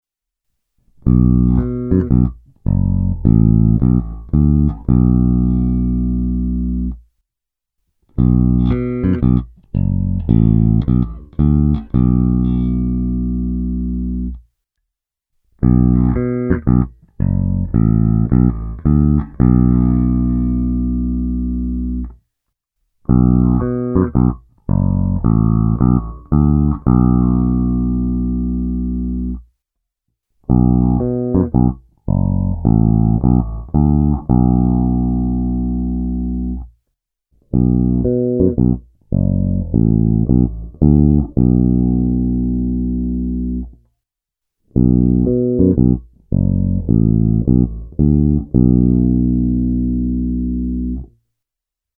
Krkový snímač